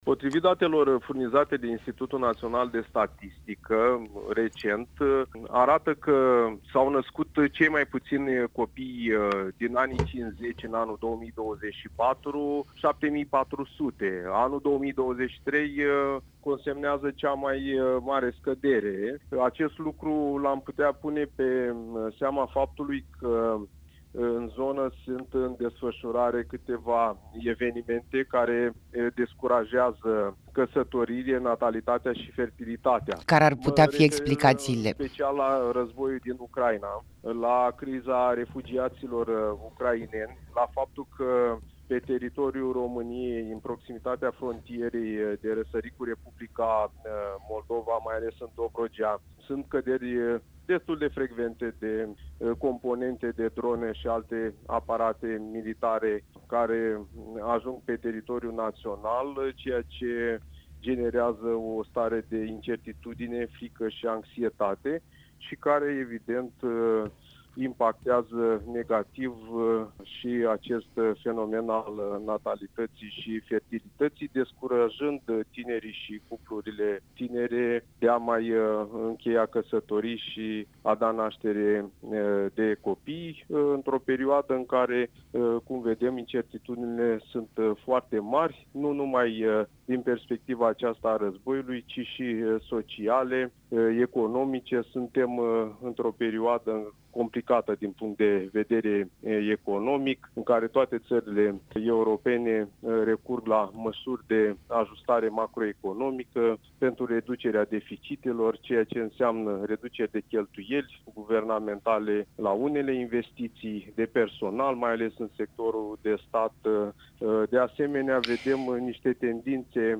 Interviu-sociolog-copii-declin-Iasi.mp3